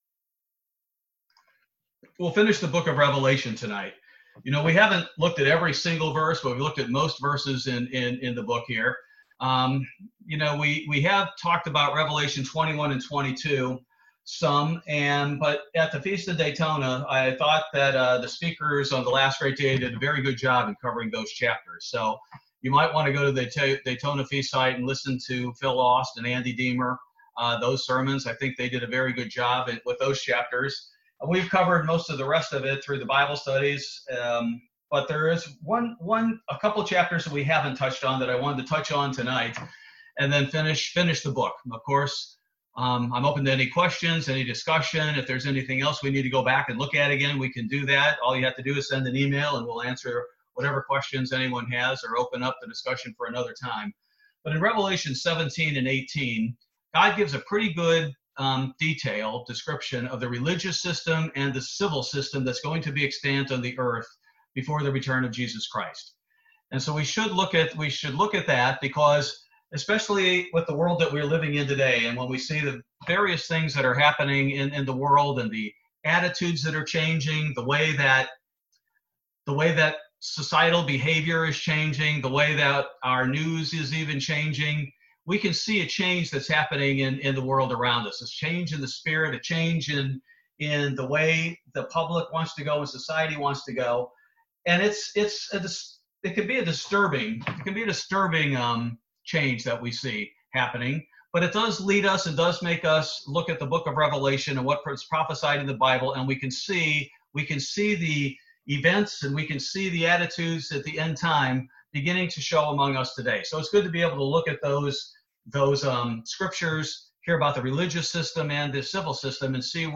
Bible Study: October 21, 2020